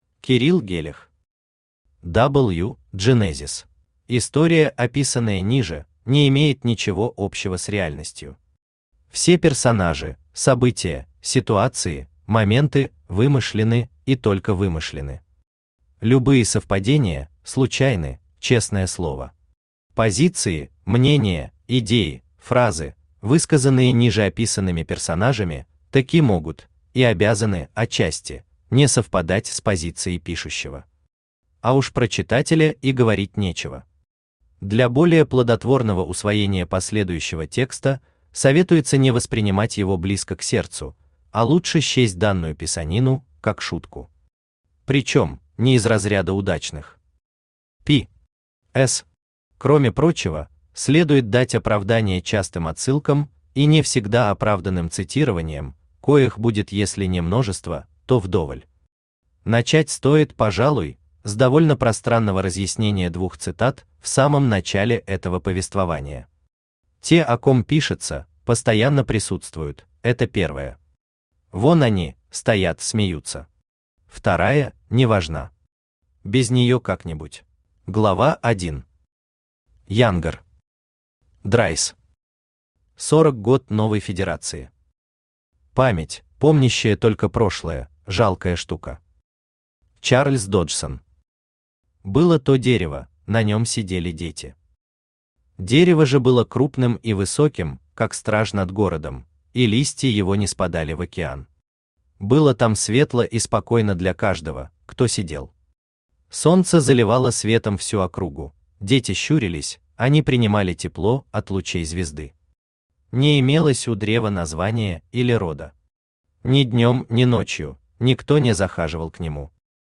Аудиокнига W: genesis | Библиотека аудиокниг
Aудиокнига W: genesis Автор Кирилл Гелех Читает аудиокнигу Авточтец ЛитРес.